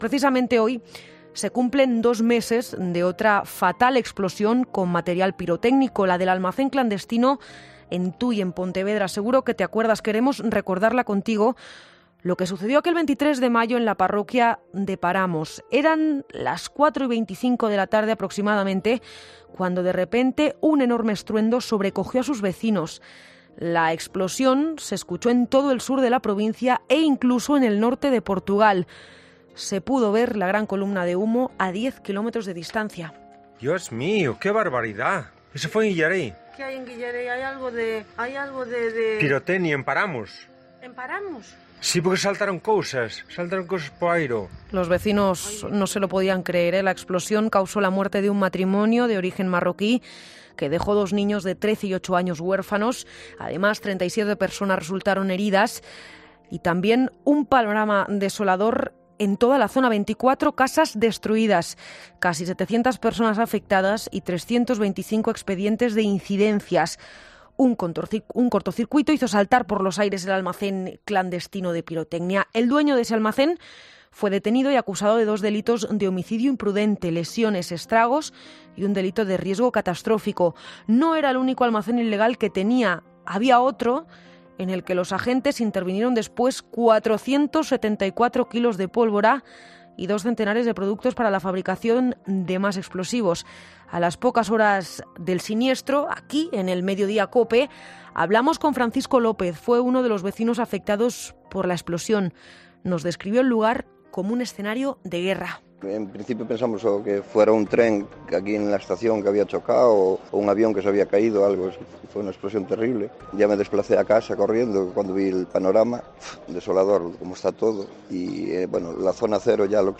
Dos meses después de la explosión de una nave clandestina de pirotecnia en Tui uno de los vecinos afectados por la explosión ha participado en 'Mediodía COPE' para comentar como está la situación del pueblo